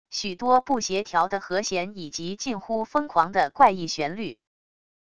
许多不协调的和弦以及近乎疯狂的怪异旋律wav音频